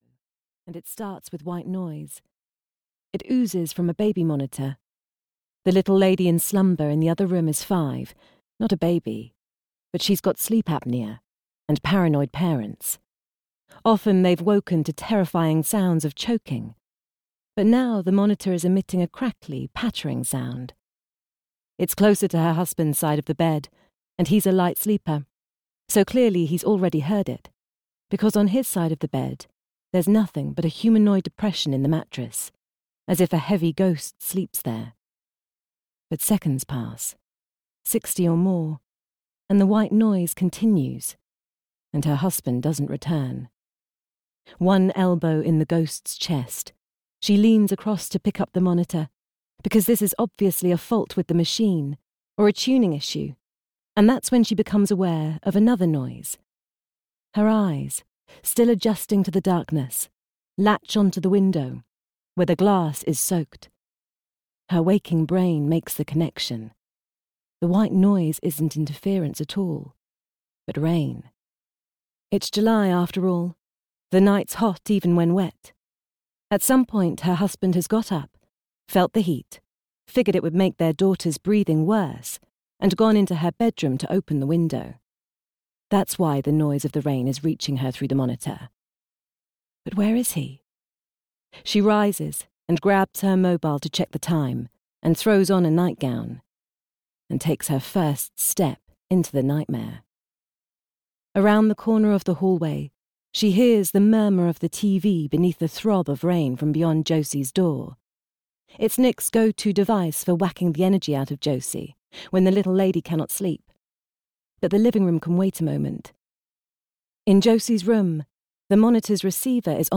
Audiobook The Family Lie, written by Jake Cross.
Ukázka z knihy